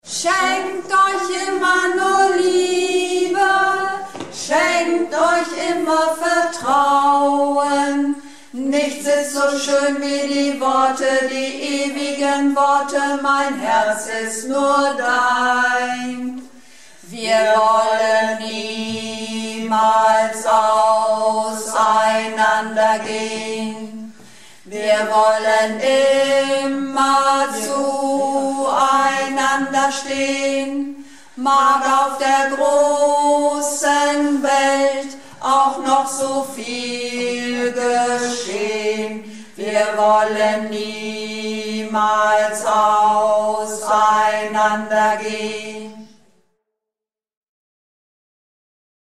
Projektchor "Keine Wahl ist keine Wahl" - Probe am 21.05.19
Runterladen (Mit rechter Maustaste anklicken, Menübefehl auswählen)   Wir wollen niemals auseinander gehn (Tiefe Stimme)